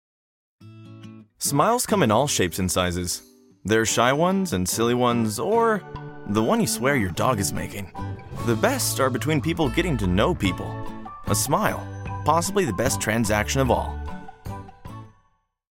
Youthful, Gen Z, energetic male voice with a natural, conversational tone.
Television Spots
Natural Observant Read
Words that describe my voice are Conversational, Young, Energetic.